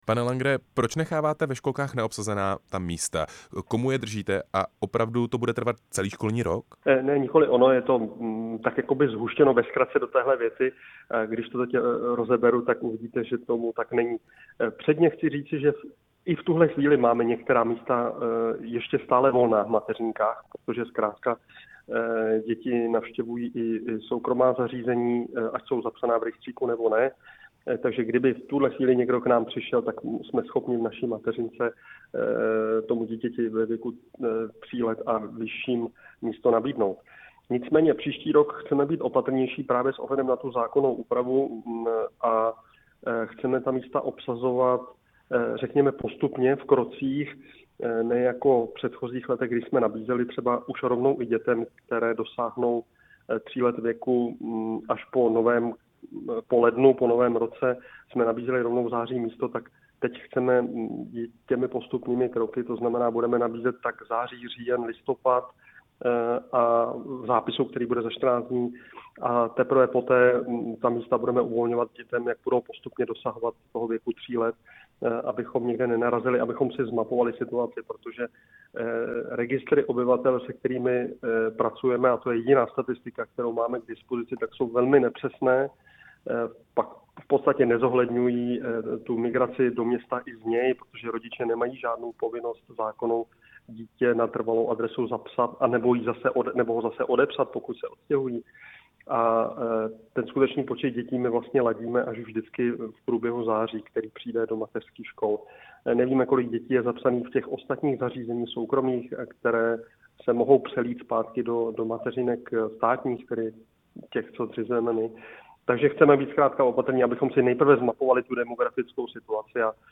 Rozhovor s primátorem Liberce Ivanem Langerem